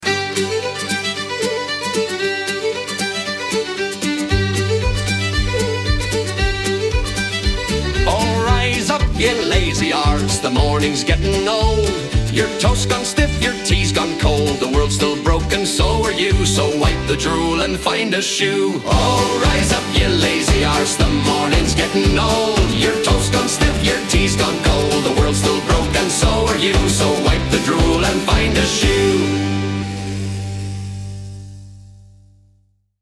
Funny Alarm Funny Irish Alarm Funny Irish Alarm